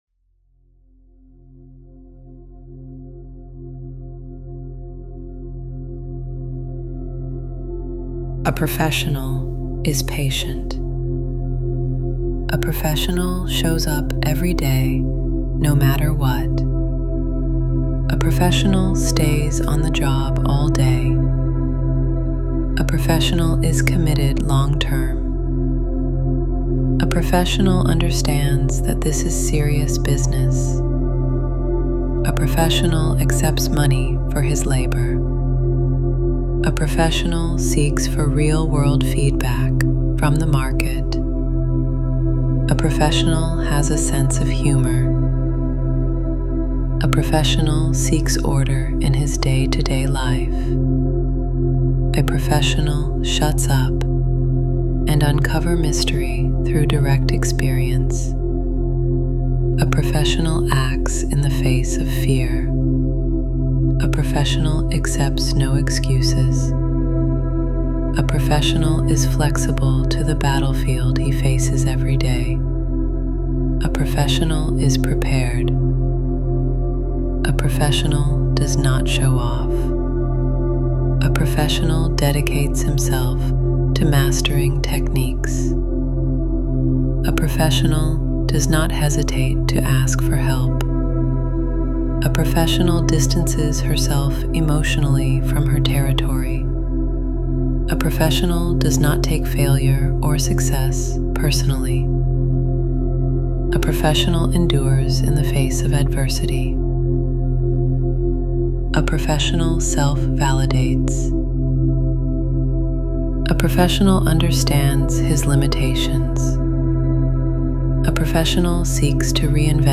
Here is a meditation I made with AI voice, combined with some meditative music I found on YouTube.